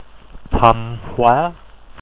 Thanh Hóa (Vietnamese: [tʰajŋ̟ hwǎː]